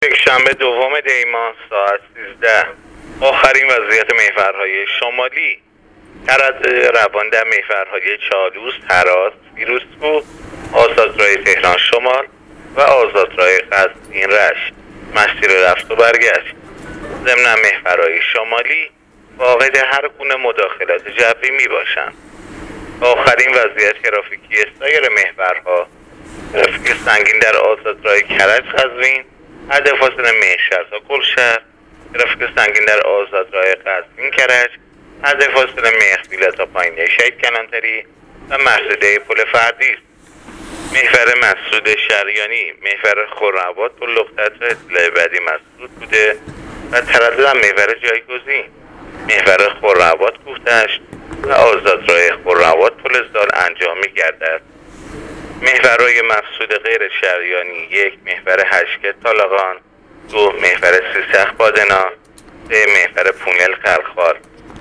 گزارش رادیو اینترنتی از آخرین وضعیت ترافیکی جاده‌ها تا ساعت ۱۳ دوم دی؛